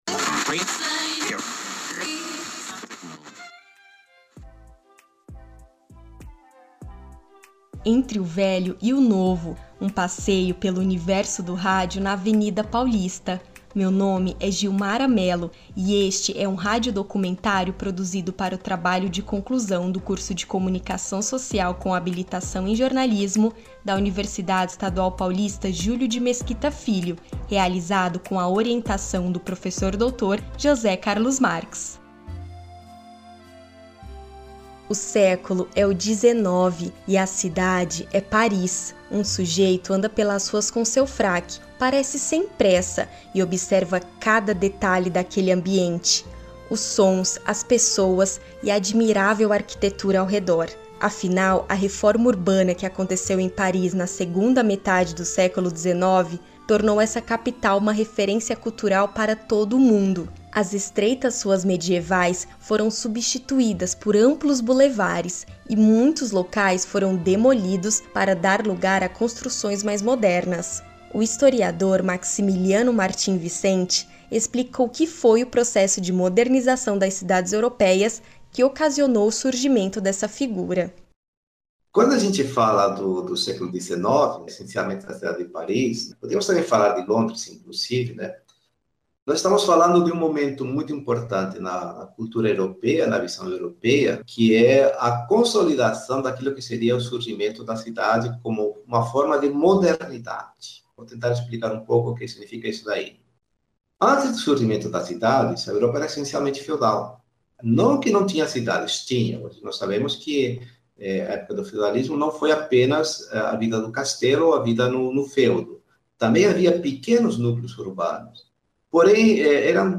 Este projeto tem como objetivo produzir um radiodocumentário sobre a importância do rádio enquanto veículo de comunicação e a sua diversidade de públicos e propostas com foco no universo radiofônico existente na Avenida Paulista.
Ao todo são seis blocos compostos por uma série de entrevistas com pesquisadores da área da comunicação, jornalistas e comunicadores com experiência no rádio.